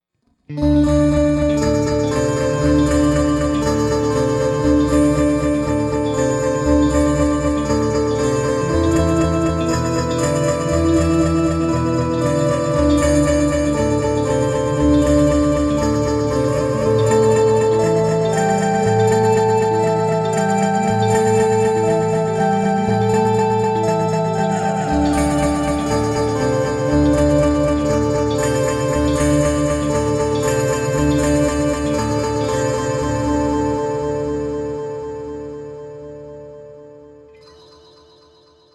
4声の美しいハーモニーとリズミカルなディレイを生み出す、直感的なピッチシフター
Quadravox | Electric Guitar | Preset: Octave Ambience
Gtr-1-Octave-Ambience.mp3